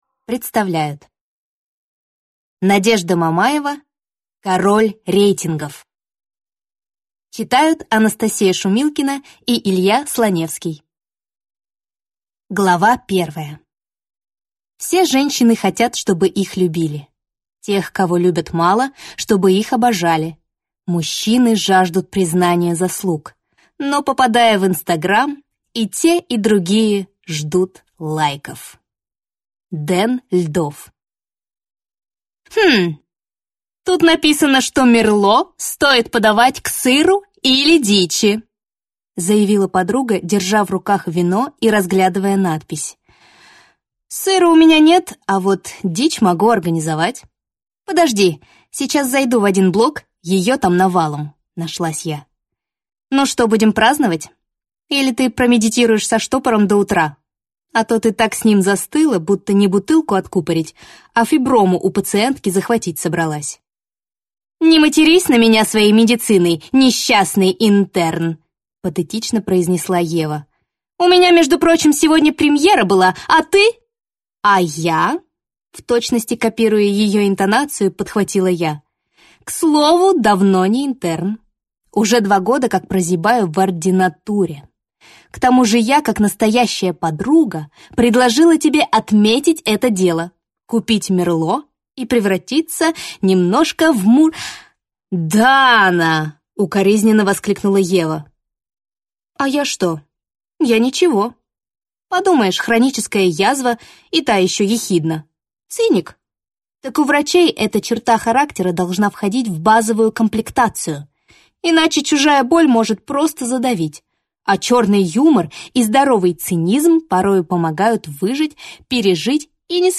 Аудиокнига Король рейтингов | Библиотека аудиокниг